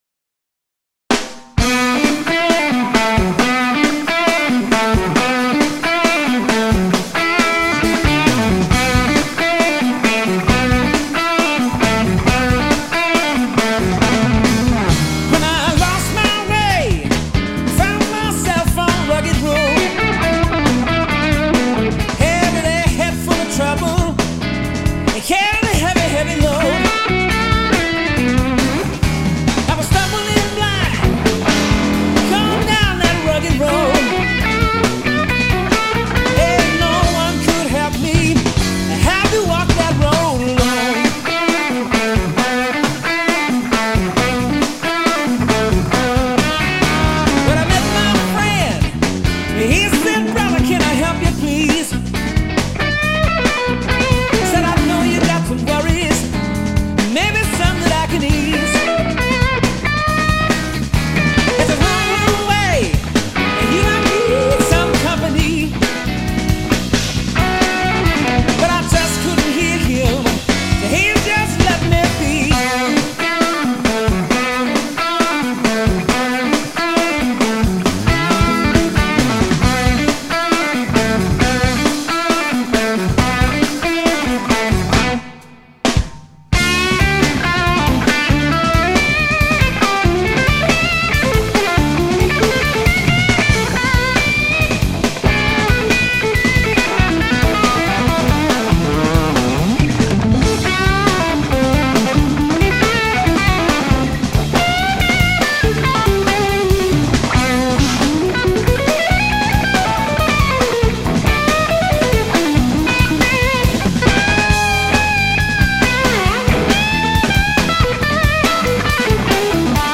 Listen to the lowend harmonic complexity on Rugged Road.